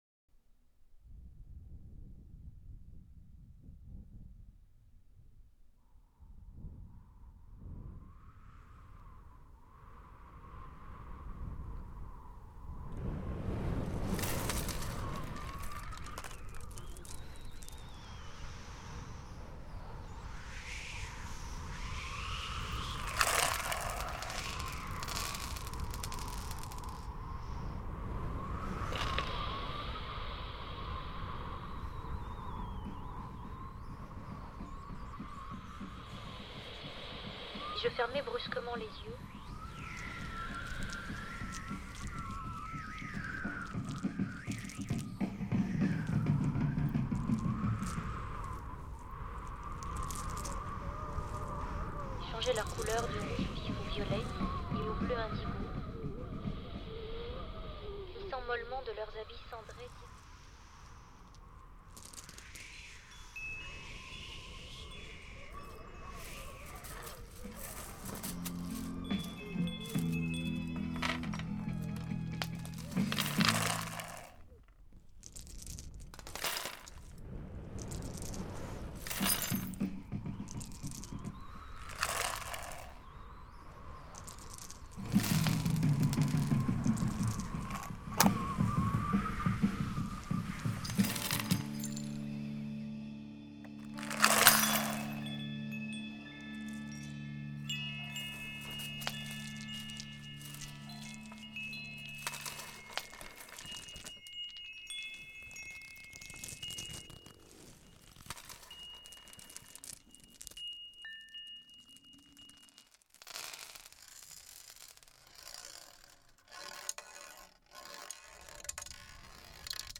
Une flamme sonore.